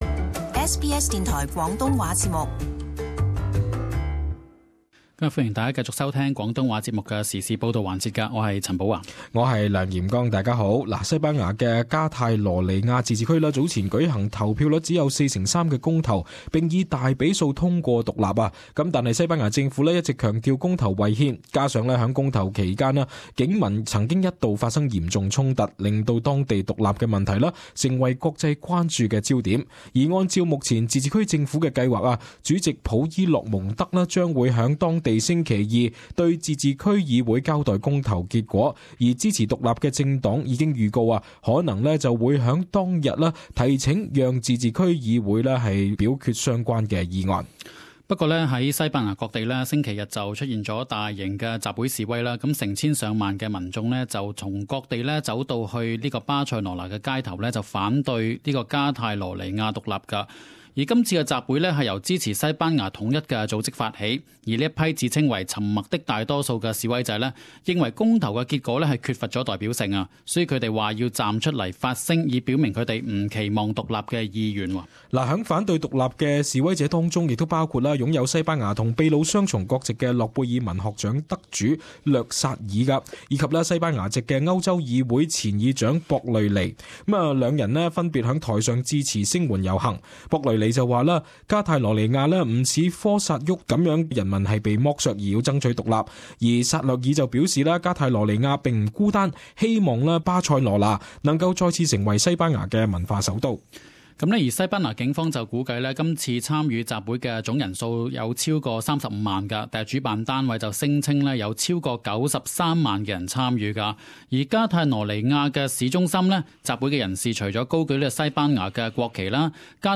【時事報導】三十五萬西班牙人上街反加泰羅尼亞獨立